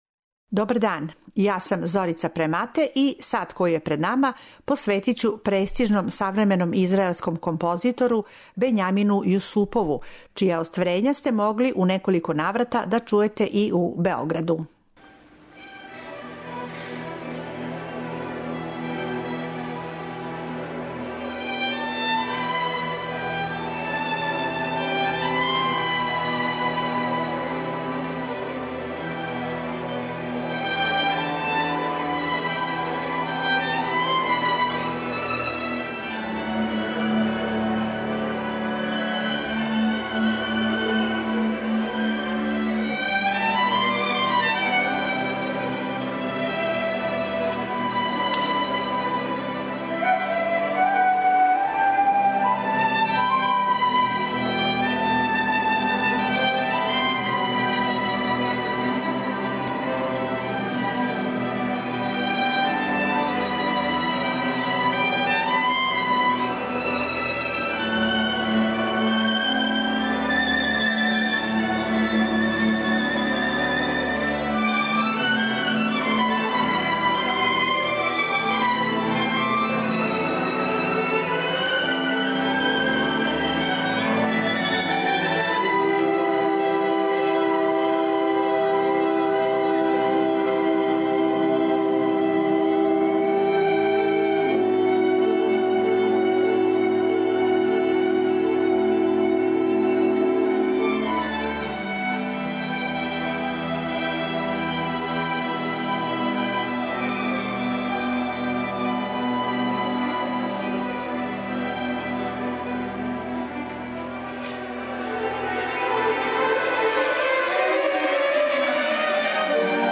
Осим одломака његових дела, међу којима ће бити и популарни "Виола Танго Рок концерт", чућете и одломак интервјуа са композитором и са виолинистом Максимом Венгеровим, који је ово остварење поручио и веома често га свира на својим наступима.